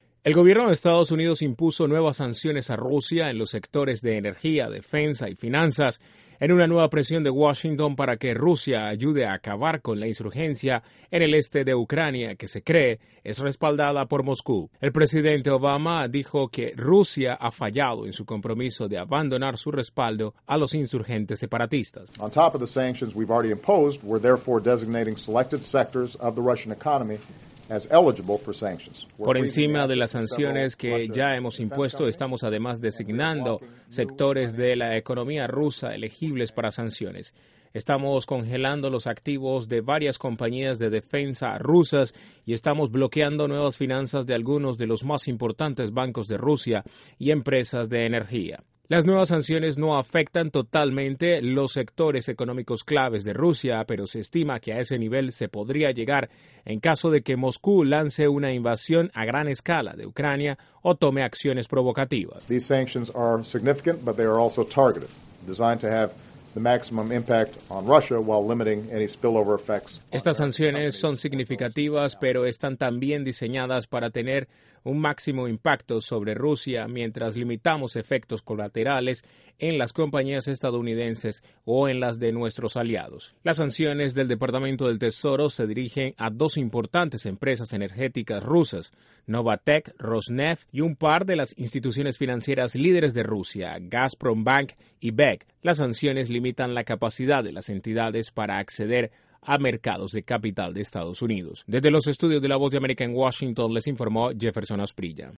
INTRO: Estados Unidos impuso nuevas sanciones económicas a Rusia, firmas energéticas y financieras entre las señaladas por el Departamento del Tesoro. Desde la Voz de América en Washington informa